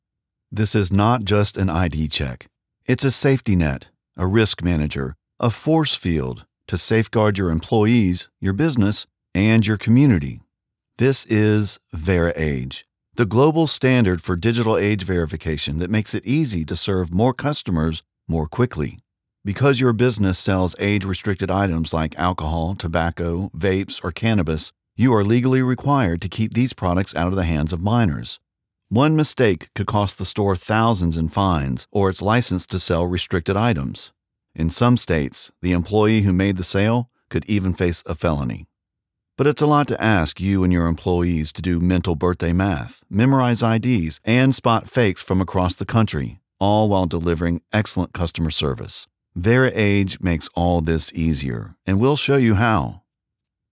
Explainer
Middle Aged